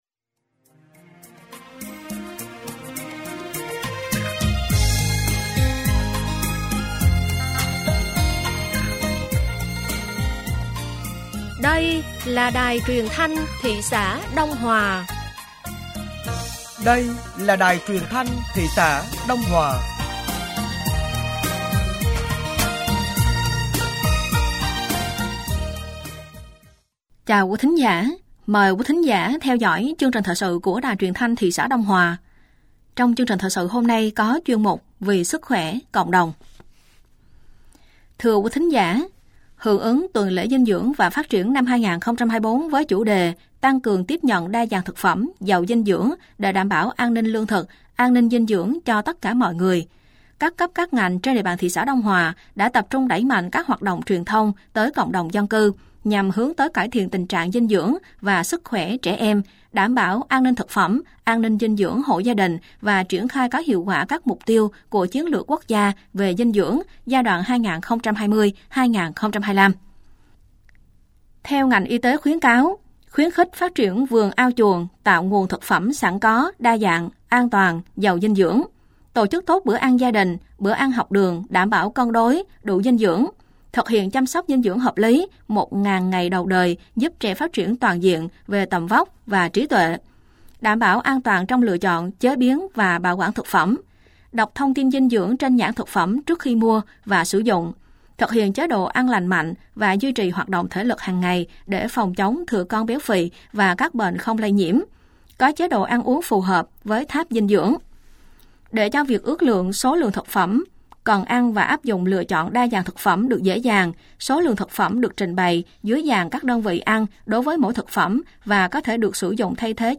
Thời sự tối ngày 10 và sáng ngày 11 tháng 11 năm 2024